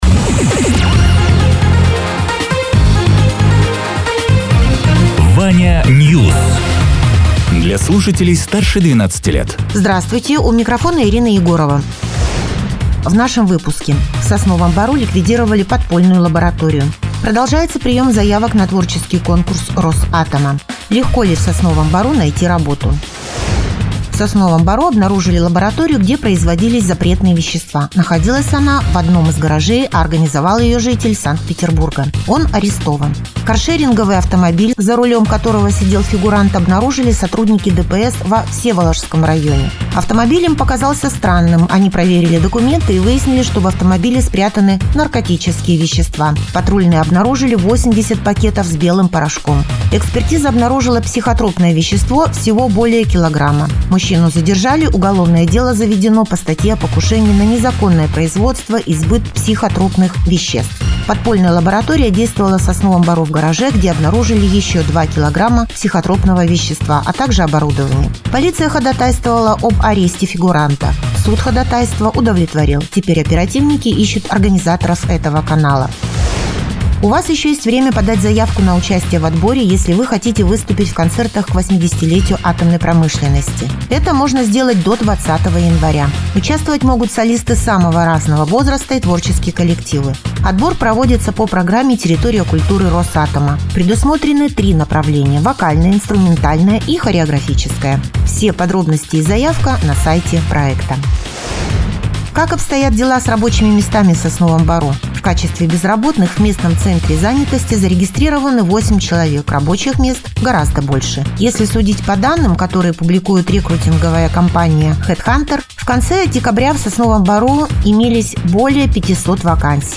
Радио ТЕРА 14.01.2025_10.00_Новости_Соснового_Бора